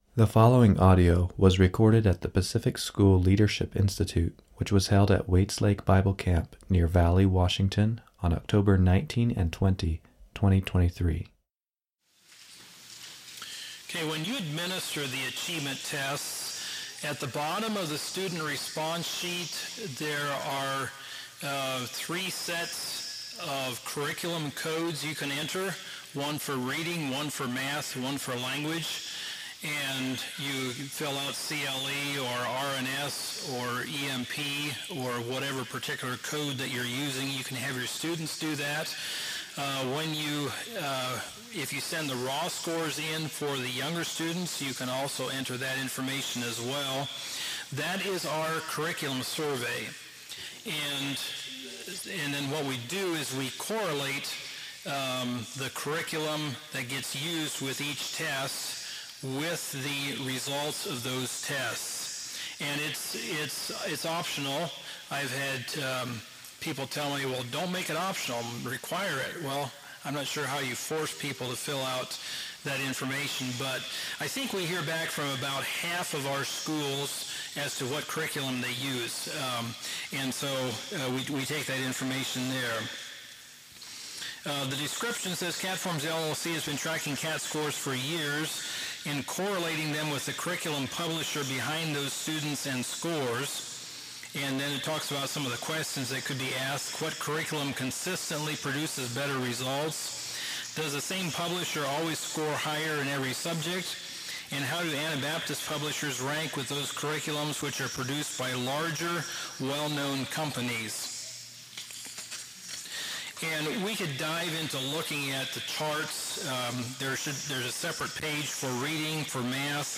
Home » Lectures » A Survey of Curriculum